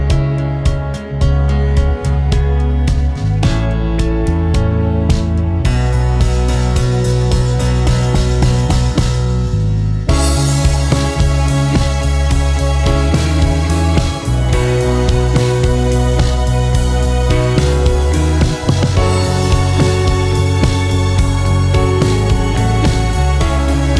One Semitone Down Pop (2010s) 4:01 Buy £1.50